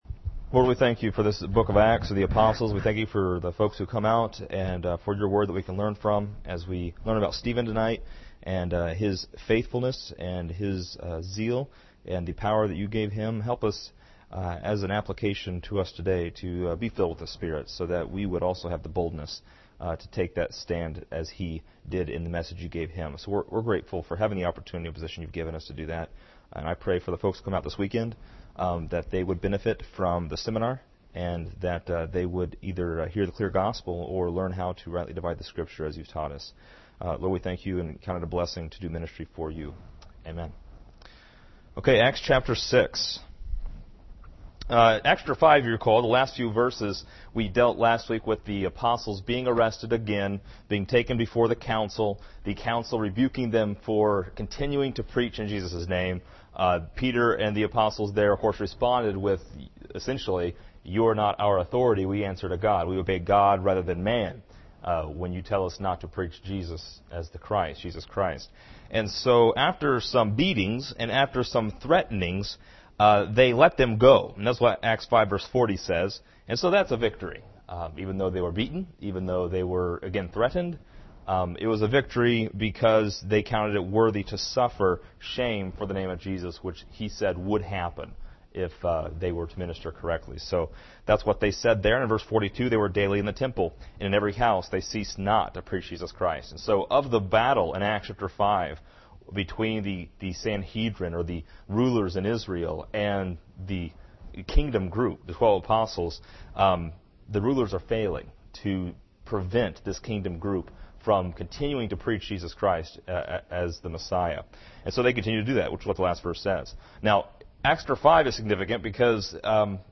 This lesson is part 16 in a verse by verse study through Acts titled: Serving Tables and Deacons.